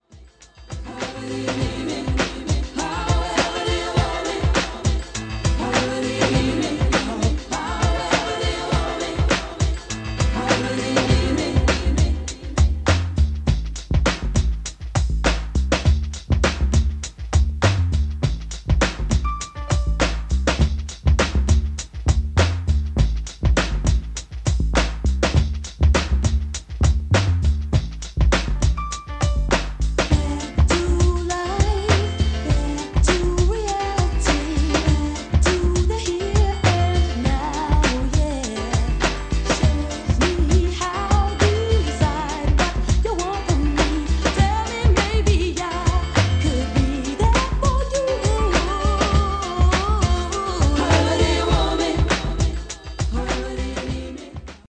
The built in HX Pro makes sure that recordings got crisp highs and on the noise reduction end we also got Dolby B & C.
Below is a test recording made with the CT-W404R and played back by it: